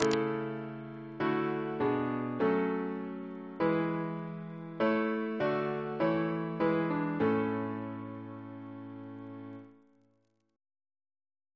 Single chant in A Composer: Reginald A. Atkins (1830-1897) Reference psalters: ACB: 109